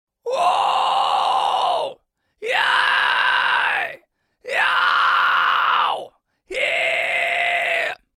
Fry Scream complexe Basique atonal
18Fry-Scream-complexe-_-Basique_atonal.mp3